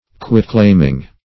Quitclaiming - definition of Quitclaiming - synonyms, pronunciation, spelling from Free Dictionary